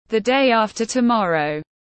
Ngày kia tiếng anh gọi là the day after tomorrow, phiên âm tiếng anh đọc là /ðə deɪ ˈɑːftə təˈmɒrəʊ/
The day after tomorrow /ðə deɪ ˈɑːftə təˈmɒrəʊ/